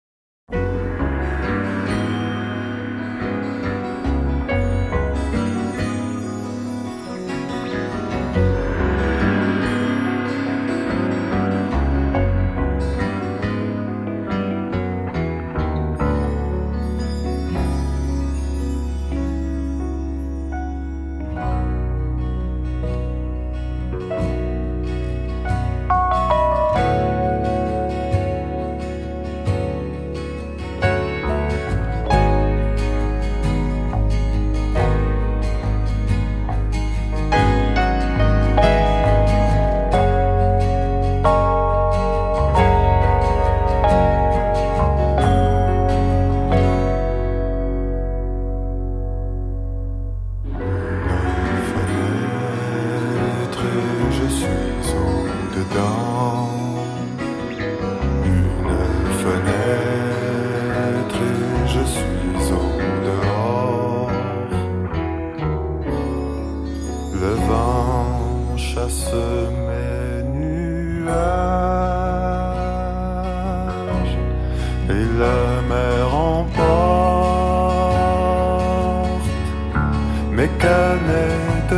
a peaceful and jazzy album